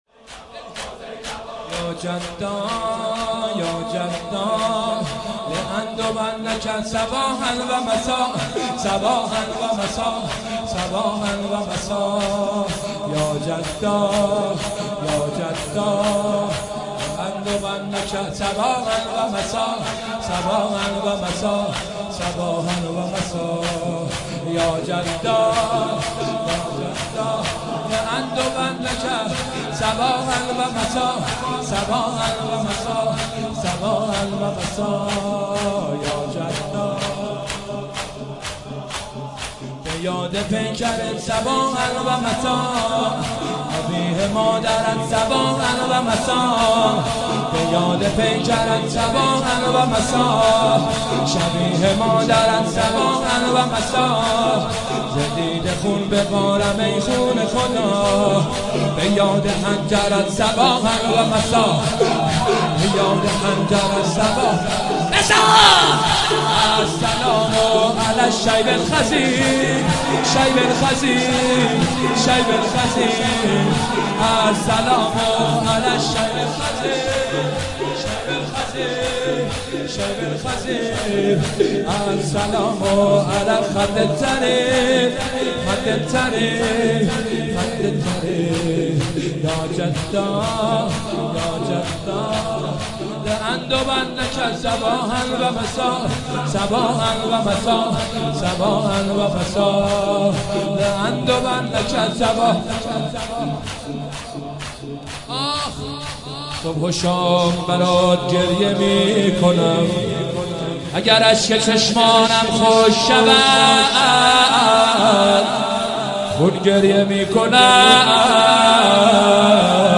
مداحی اربعین
شور